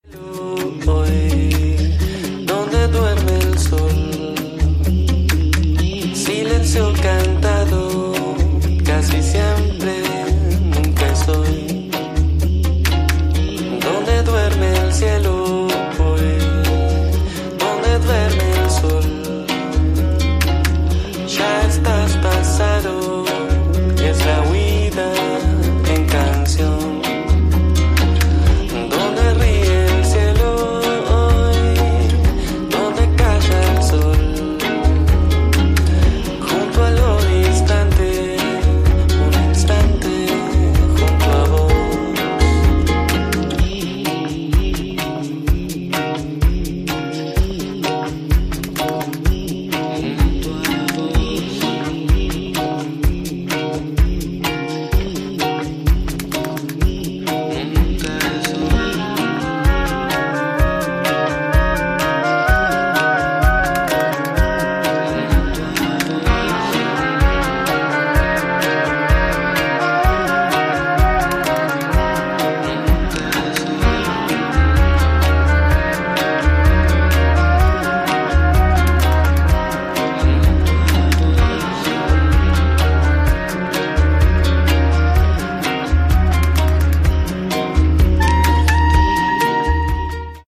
Balearic & Downtempo flavours
Electronix House